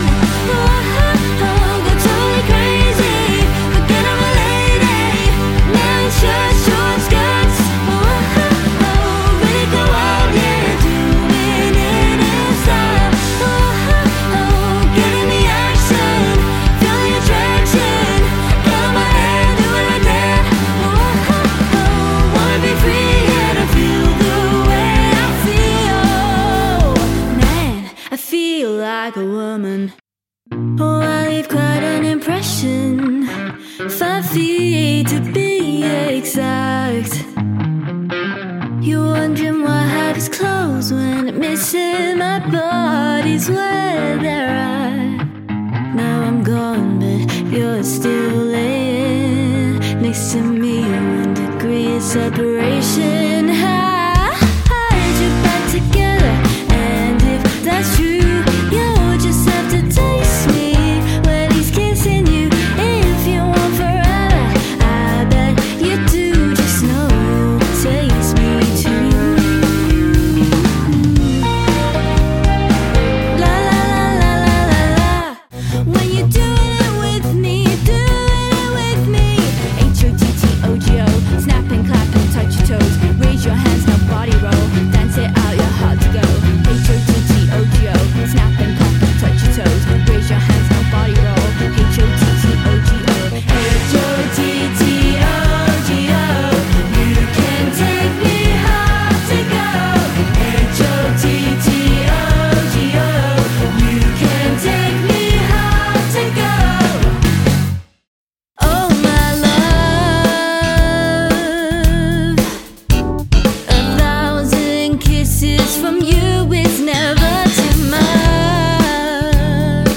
• Exciting and energetic live show
• Both female and male vocalists
Female Vocals, Male Vocals / Bass, Guitar, Keys, Drums